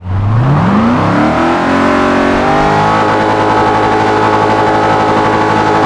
rev.wav